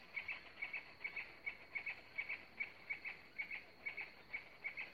Carpintero del Cardón (Melanerpes cactorum)
Nombre en inglés: White-fronted Woodpecker
País: Argentina
Condición: Silvestre
Certeza: Vocalización Grabada